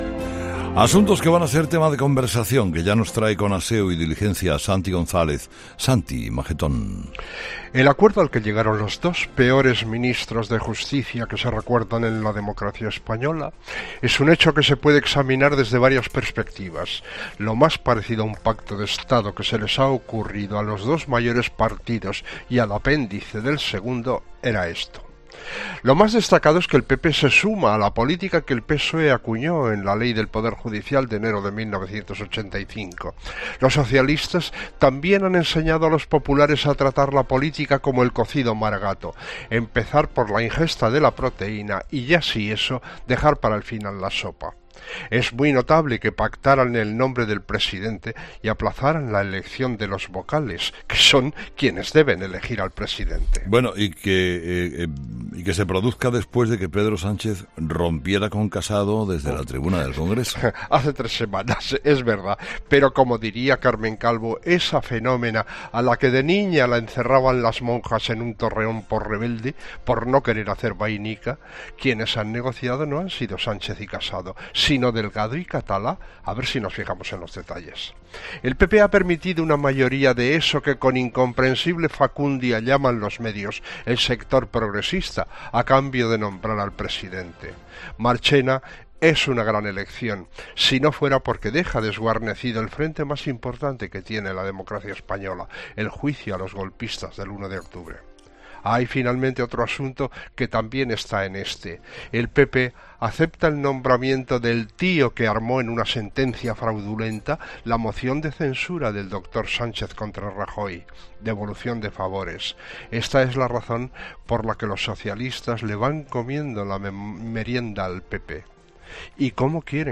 El nombramiento del presidente del CGPJ y el polémico reparto de sus miembros entre PP y PSOE, centran el análisis del tertuliano
El comentario de Santi González del martes 13 de noviembre de 2018